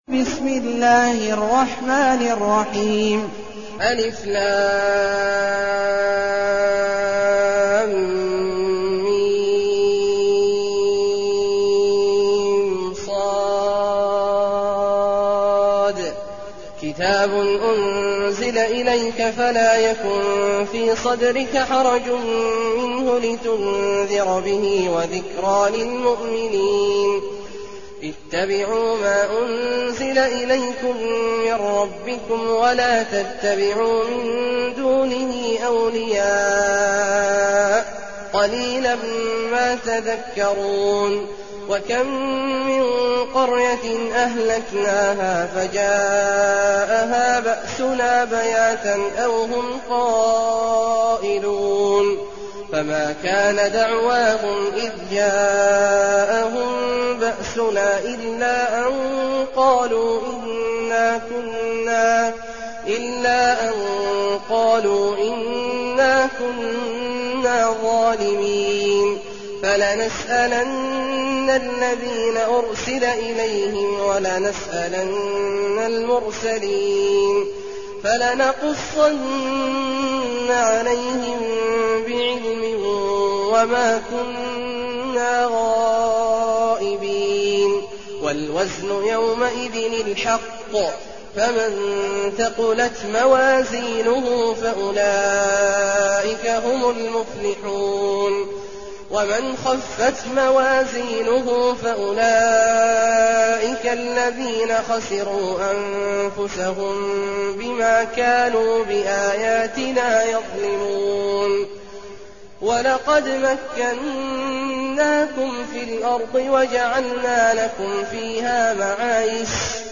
المكان: المسجد النبوي الشيخ: فضيلة الشيخ عبدالله الجهني فضيلة الشيخ عبدالله الجهني الأعراف